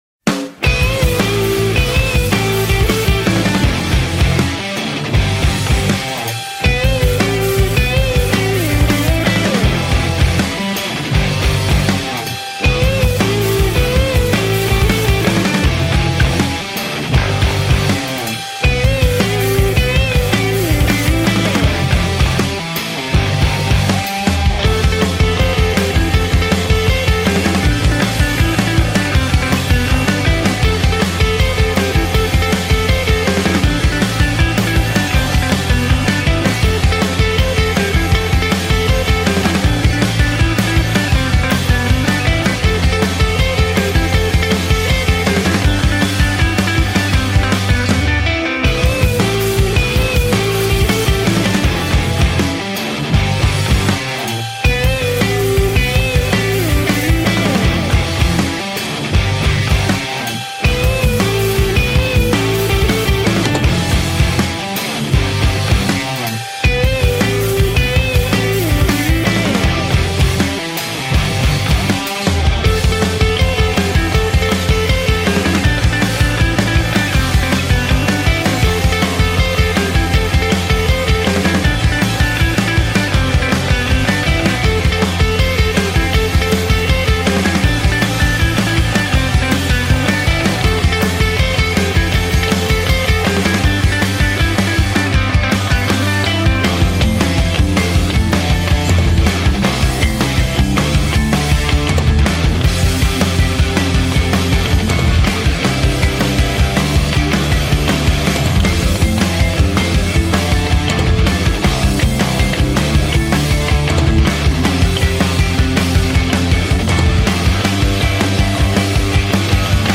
instrumental rock band
four atmospheric instrumental compositions and a cover song